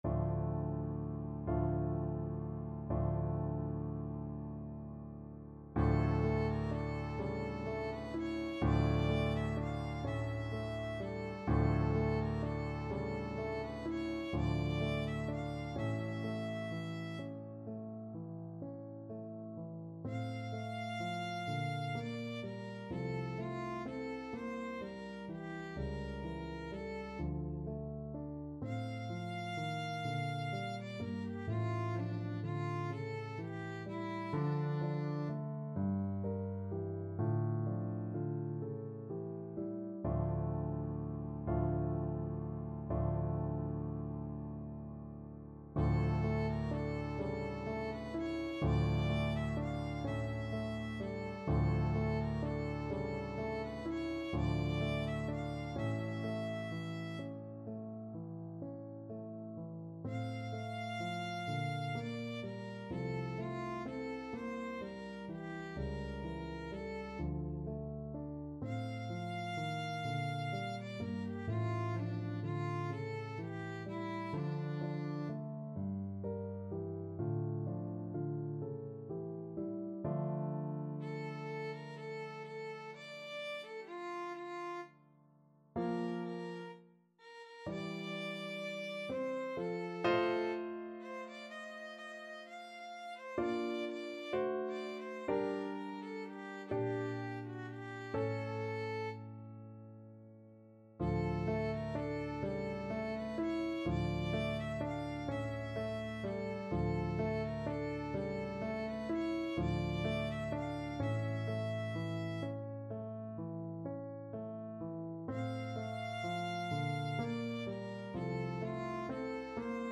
Classical Schubert, Franz Der Abend, D.108 Violin version
Violin
D minor (Sounding Pitch) (View more D minor Music for Violin )
. = 42 Andante con moto (View more music marked Andante con moto)
6/8 (View more 6/8 Music)
D5-F6
Classical (View more Classical Violin Music)